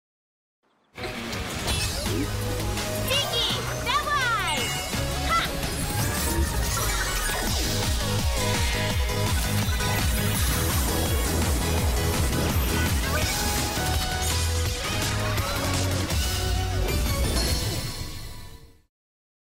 под музыку и голос